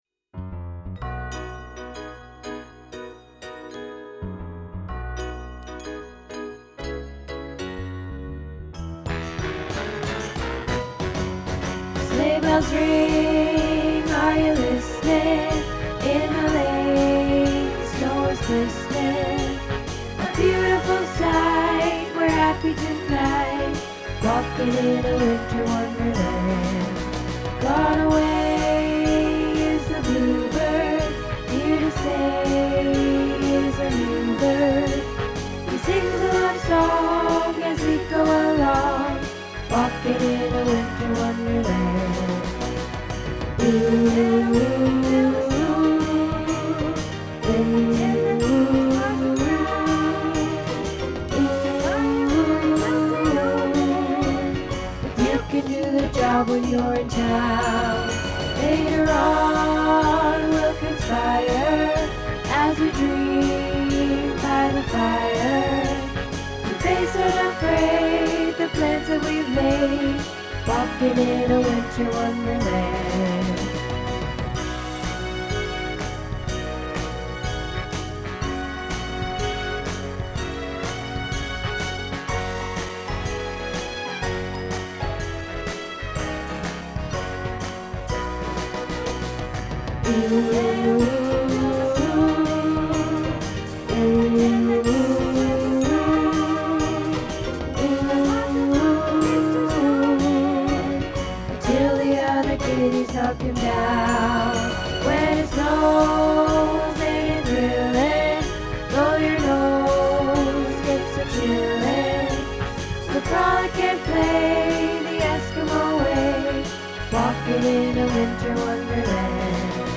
Winter Wonderland Bass